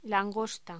Locución: Langosta
voz